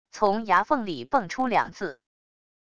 从牙缝里蹦出两字wav音频